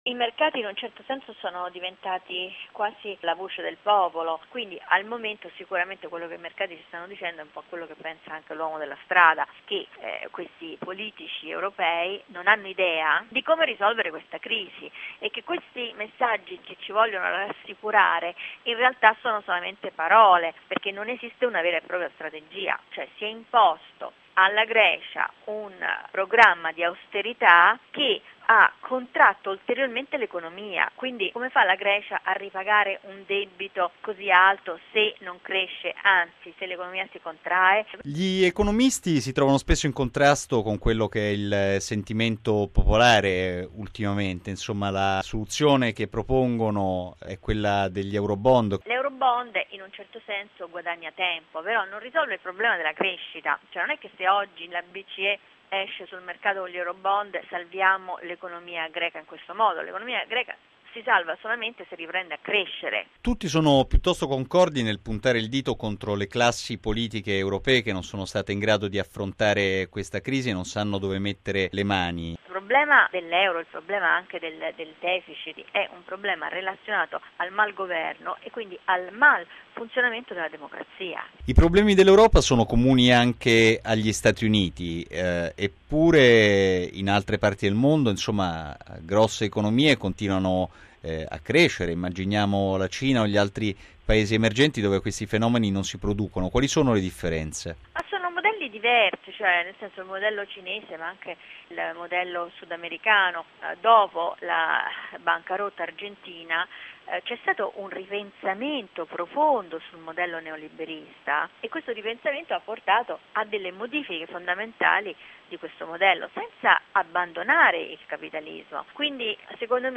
E un quadro della crisi in corso viene tracciato dall’economista Loretta Napoleoni, nel suo nuovo libro intitolato “Il contagio”.